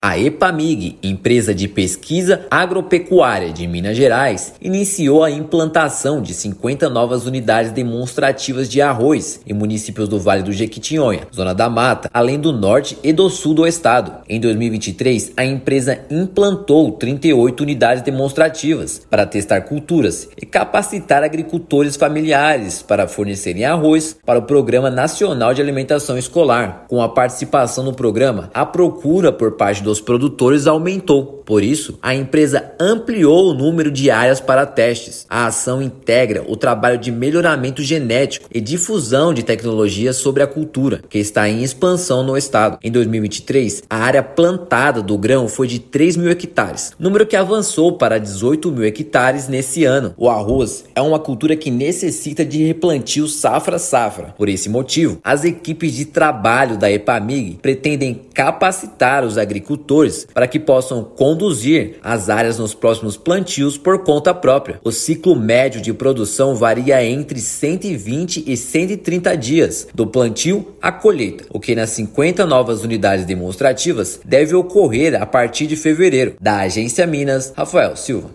Ações são voltadas para agricultores familiares, especialmente do Norte do estado e do Vale do Jequitinhonha. Ouça matéria de rádio.